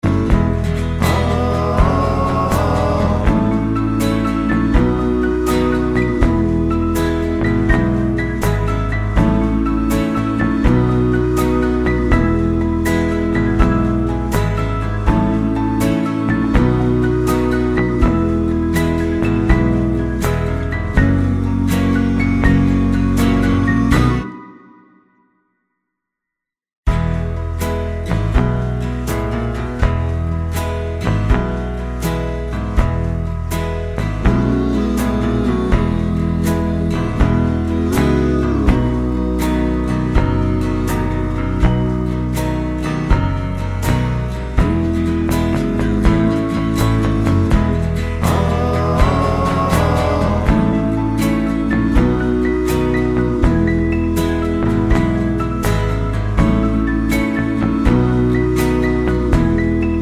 No Harmony Pop (1960s) 2:37 Buy £1.50